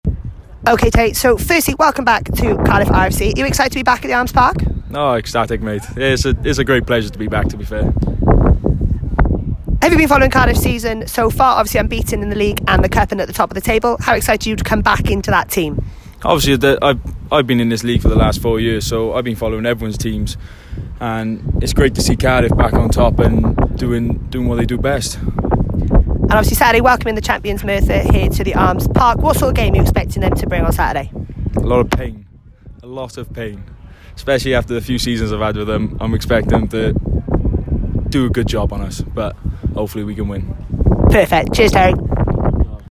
Pre Game Interview.